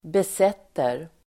Uttal: [bes'et:er]